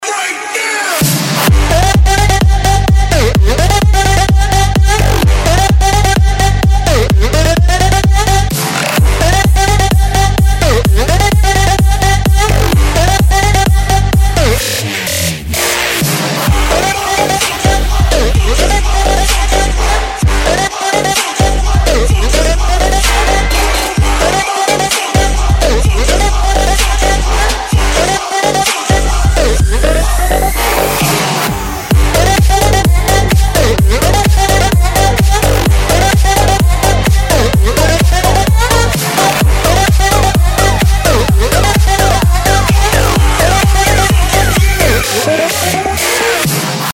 • Качество: 320, Stereo
Dutch House и Trap в одном треке!